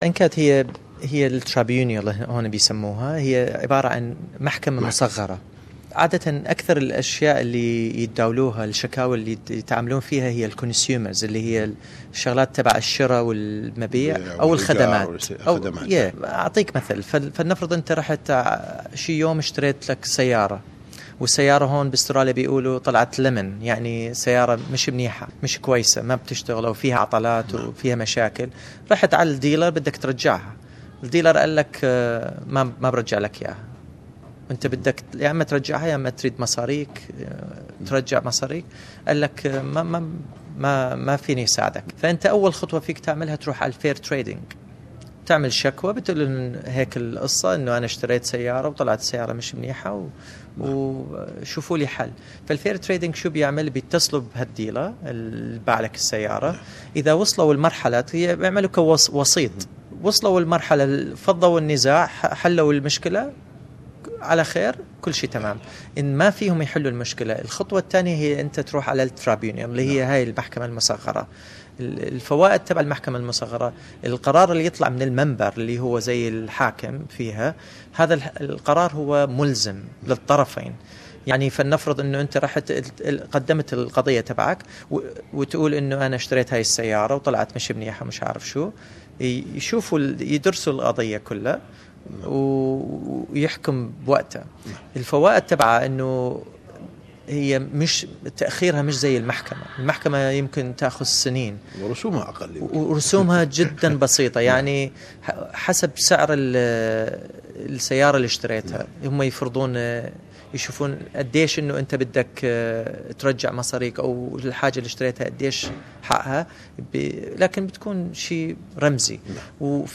speaks to SBS Arabic 24 about NCAT